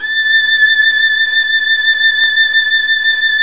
Index of /90_sSampleCDs/Roland L-CD702/VOL-1/STR_Violin 2&3vb/STR_Vln2 mf vb
STR  VL A 7.wav